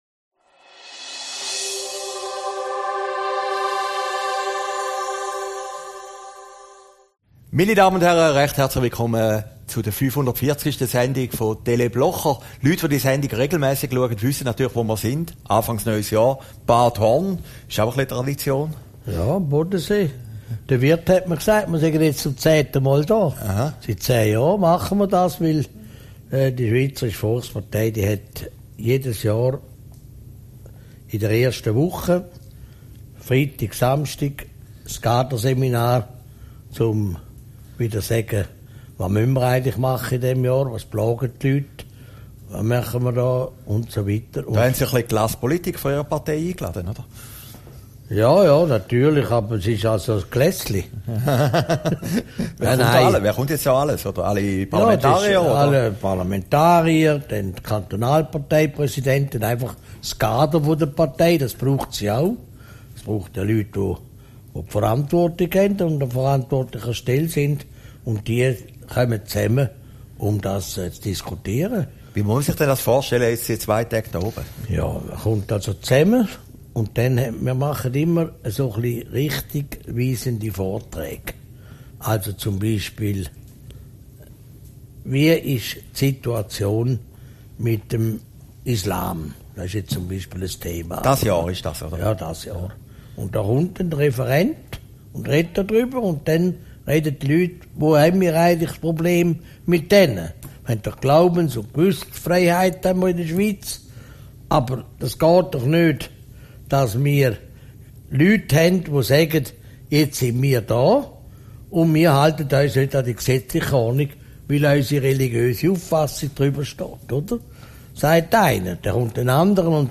Aufgezeichnet in Bad Horn, 5. Januar 2018